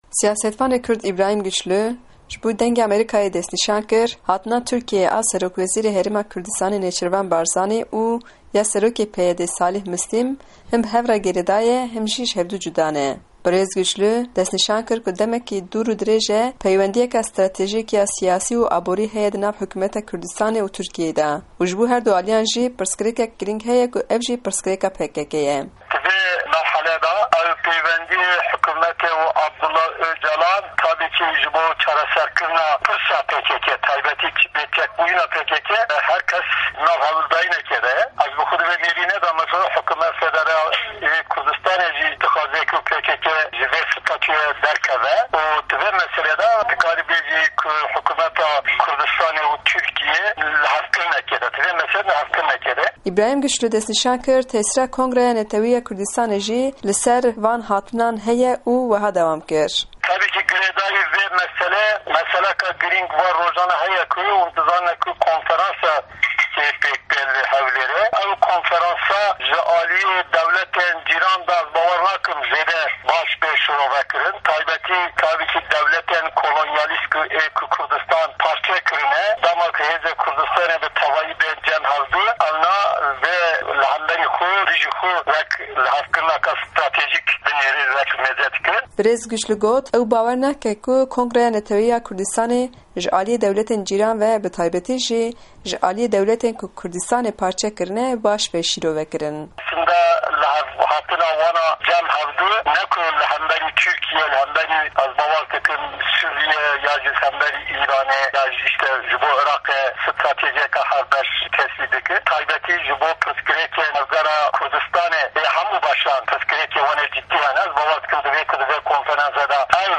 Hevpeyvîn